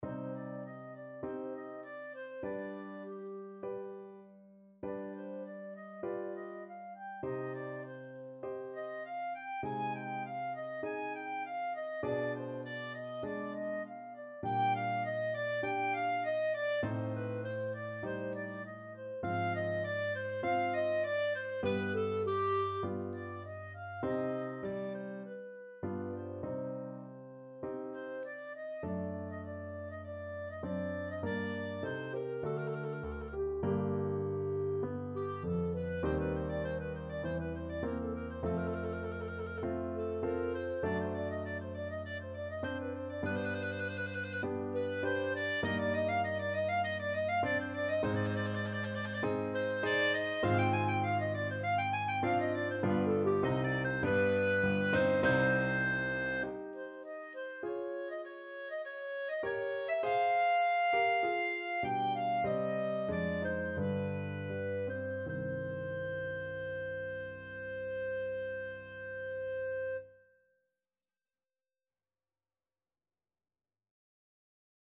Clarinet version
4/4 (View more 4/4 Music)
Largo
Classical (View more Classical Clarinet Music)